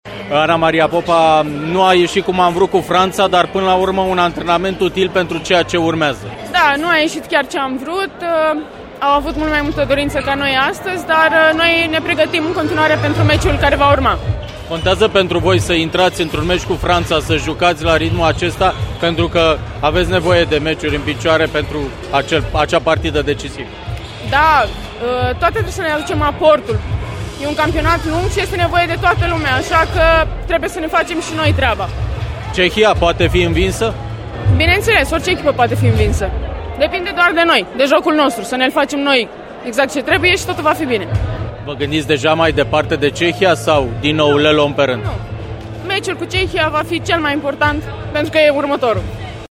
Jucătoarea României